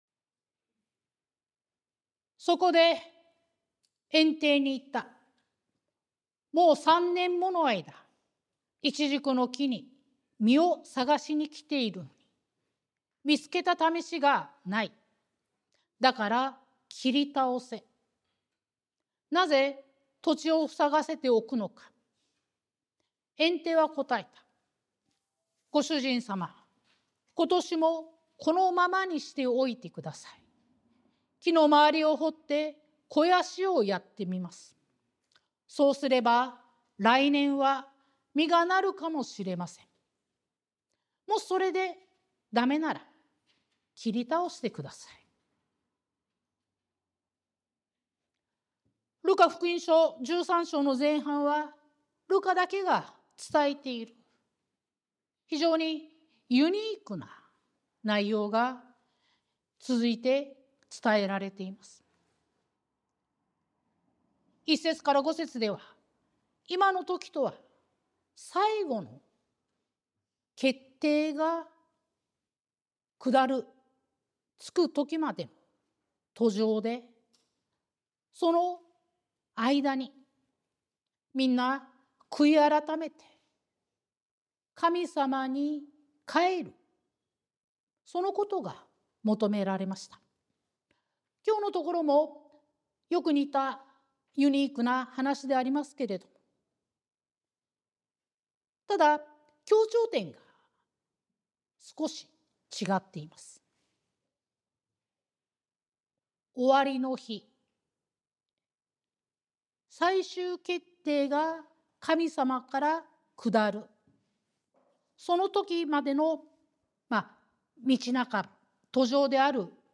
sermon-2025-05-04